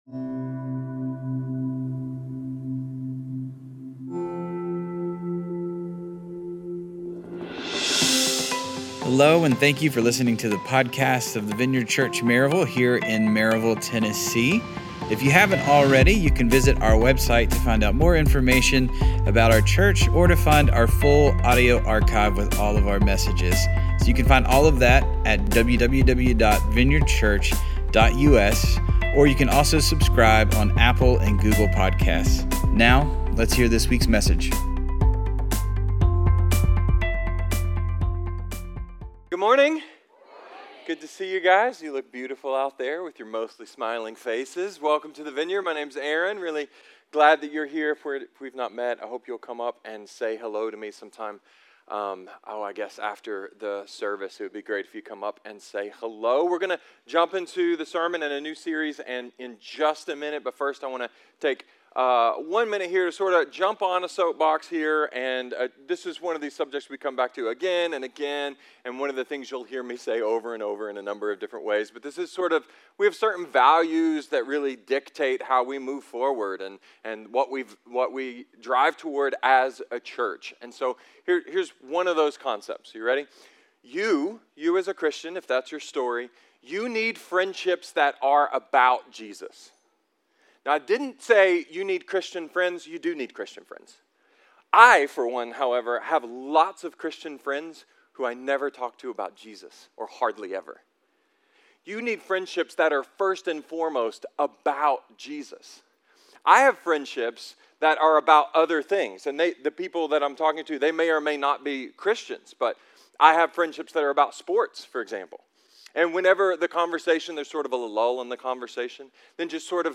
A sermon about a fallen soldier, a ridiculous mission, and how sometimes crazy, is crazy good.